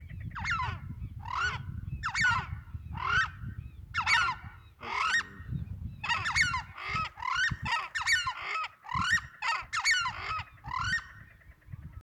Southern Screamer (Chauna torquata)
Detailed location: Reserva Privada El Cencerro
Condition: Wild
Certainty: Observed, Recorded vocal